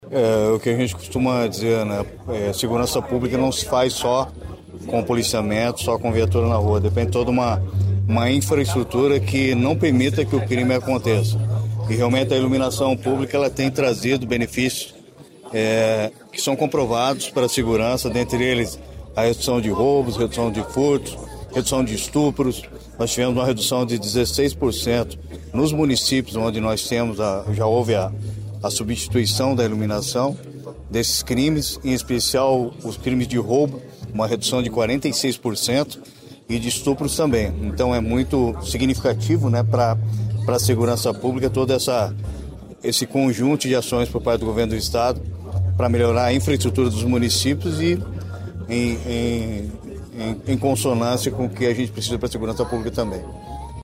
Sonora do secretário Estadual da Segurança Pública, Hudson Teixeira, sobre as liberações desta quarta pelo programa Ilumina Paraná